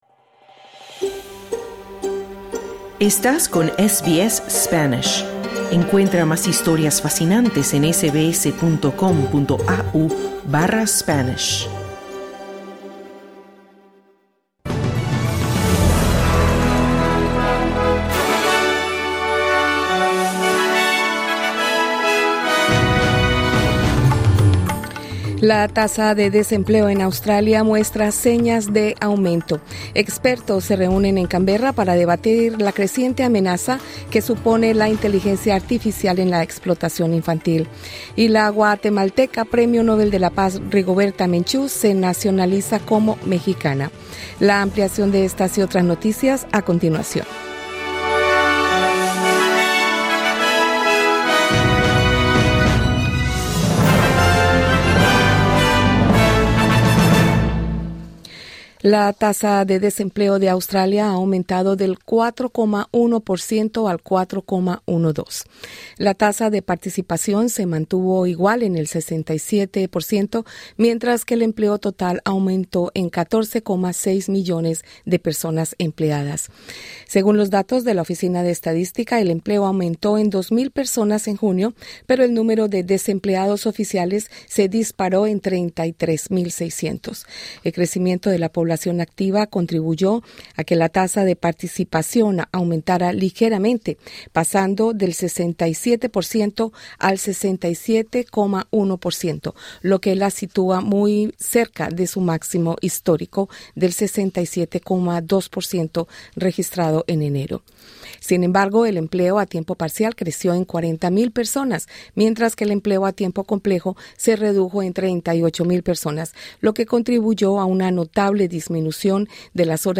Para escuchar el boletín de noticias, presiona el botón de reproducción de audio que aparece al inicio de esta página.